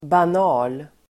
Uttal: [ban'a:l]